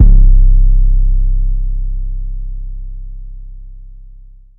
Paper_Chasin_808.wav